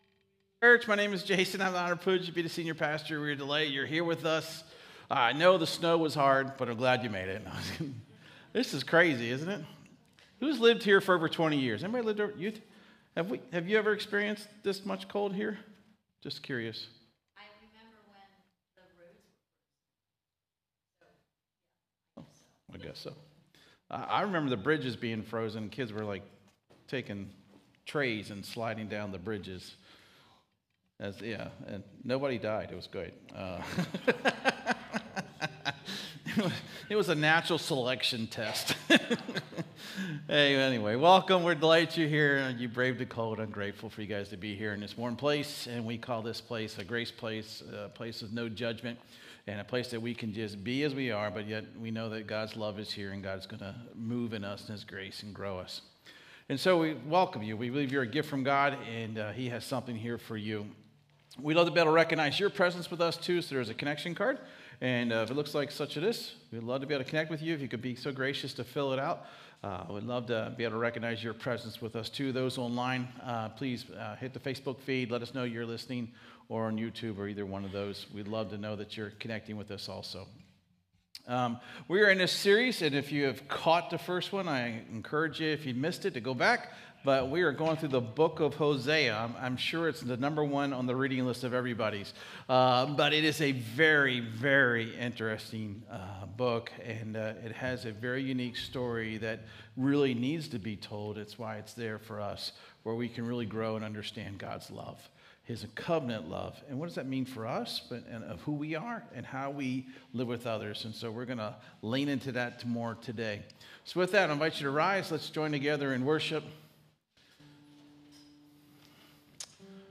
98 Campus | Hope on the Beach Church